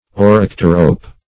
Search Result for " orycterope" : The Collaborative International Dictionary of English v.0.48: Orycterope \O*ryc"ter*ope\, n. [Gr.
orycterope.mp3